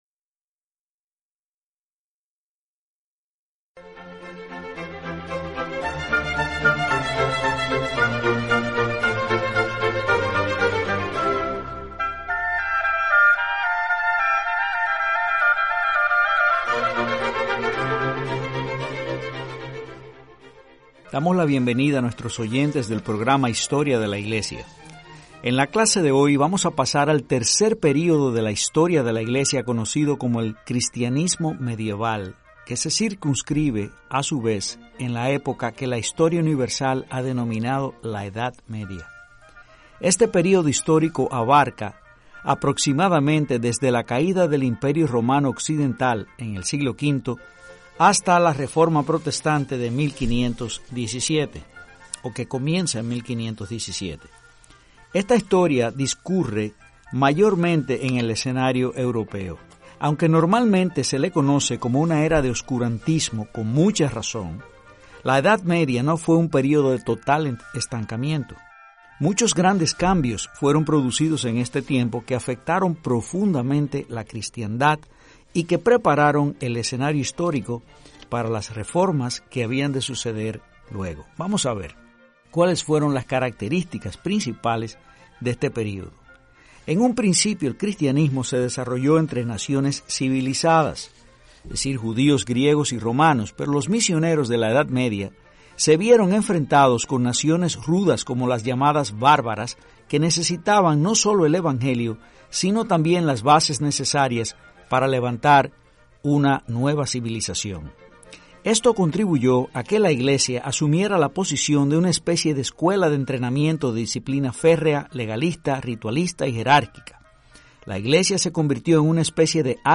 Serie de 29 exposiciones divulgativas emitidas por Radio Eternidad